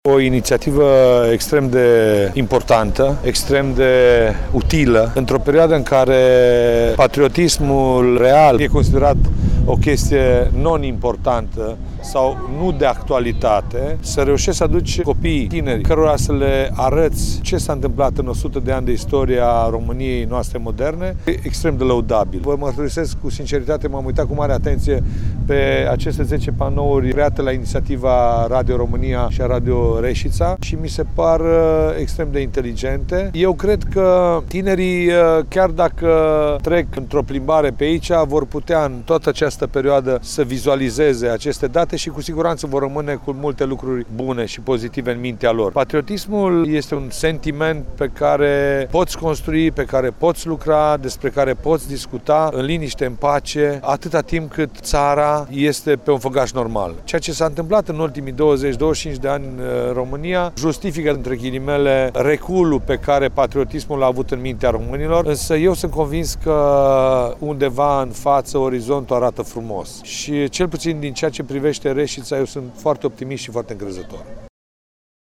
Sâmbătă, 6 octombrie de la ora 17.00 a avut loc o lecţie de istorie în aer liber susţinută la panourile amplasate în centrul civic.
Ioan Popa – primarul municipiului Reşiţa: